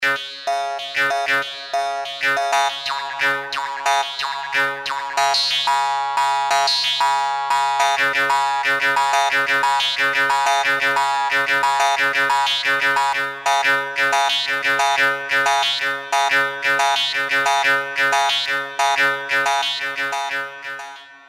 Morsing (jews harp) [the links are to audio clips from Swar Systems that demonstrate the sounds of these instruments - ed.]
morsing.mp3